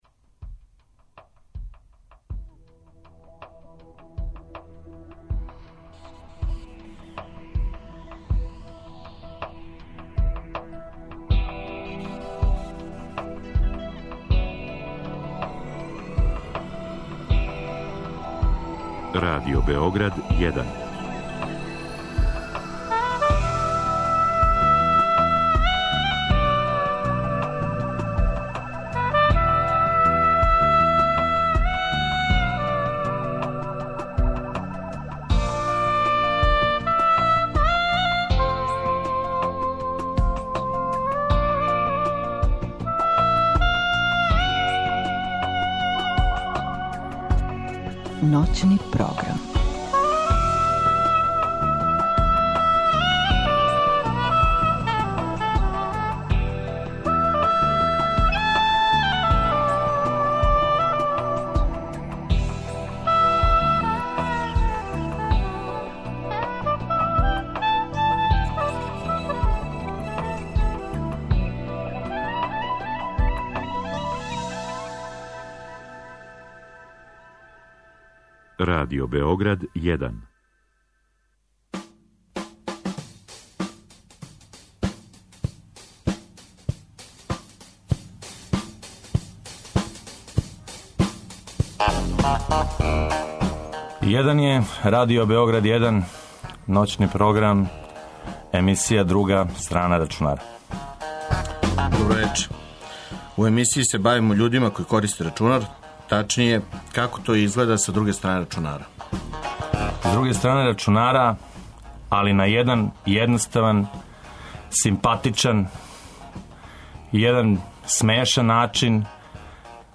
Емисија се на забаван начин бави особама које користе рачунар. Овог пута се упознајемо са слушаоцима и одговарамо на питања.